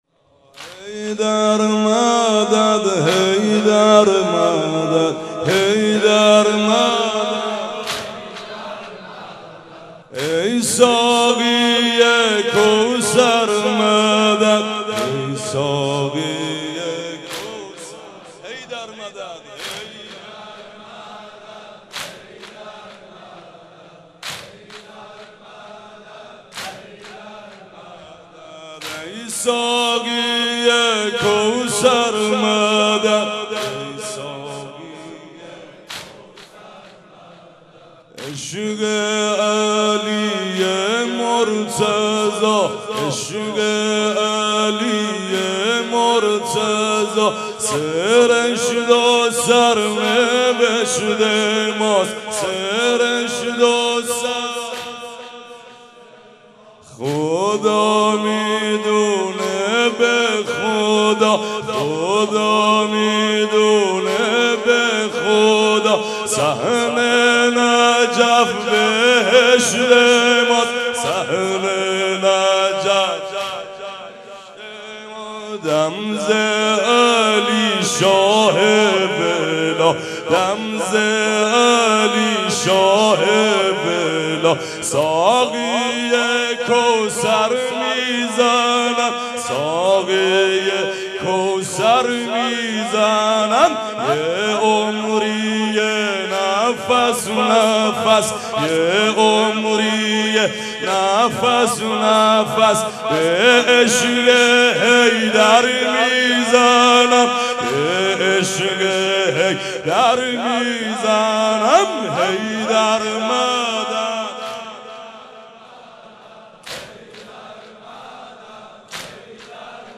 مناسبت : شب بیست و دوم رمضان